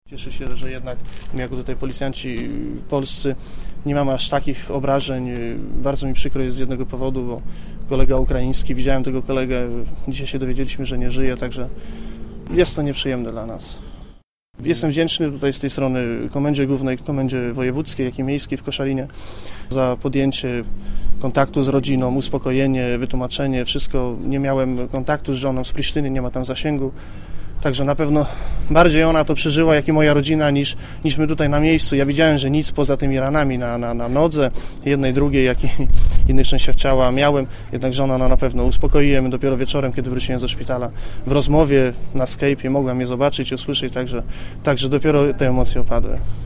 Relacja policjanta z województwa zachodniopomorskiego, który pełni służbę w Kosowie: